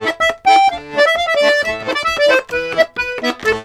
Index of /90_sSampleCDs/USB Soundscan vol.40 - Complete Accordions [AKAI] 1CD/Partition C/04-130POLKA
C130POLKA2-L.wav